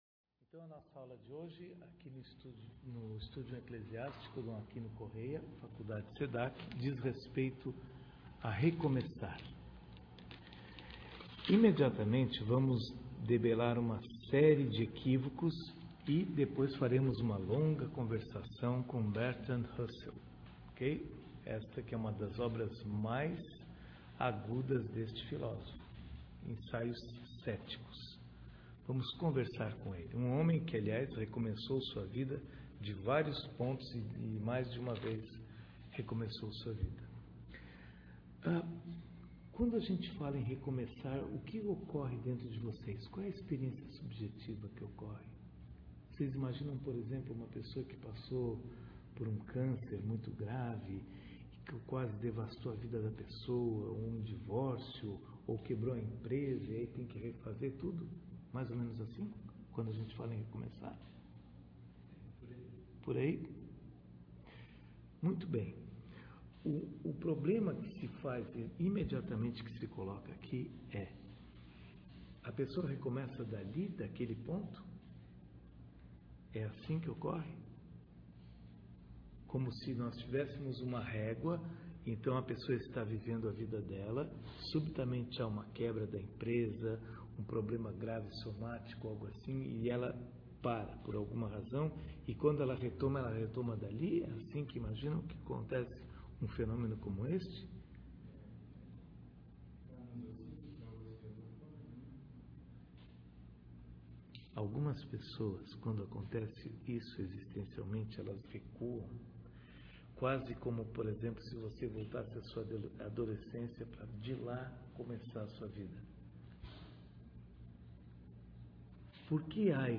Faculdade Sedac, Cuiab�. dezembro de 2015
Para ouvir a oficina realizada, clique aqui.